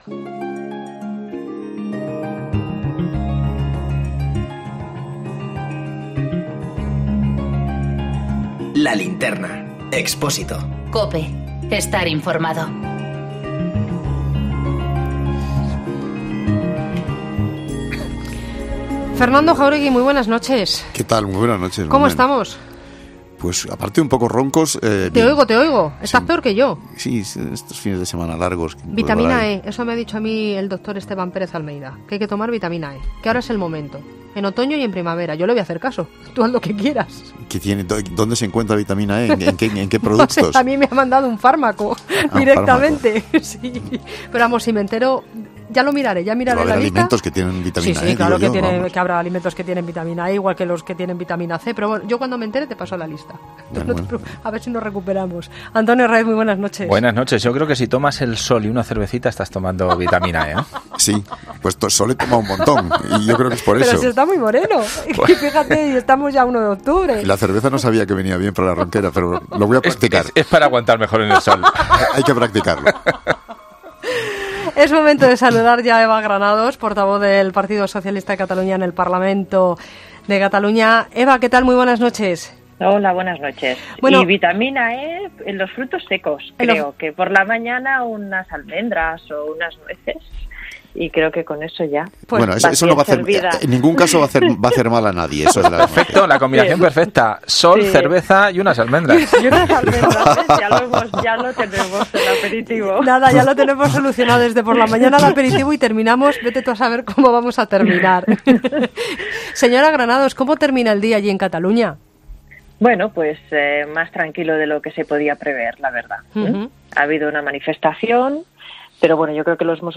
La portavoz del partido socialista de Cataluña en el Parlament habla sobre lo que ha sido esta jornada del 1-O en 'La Linterna de COPE'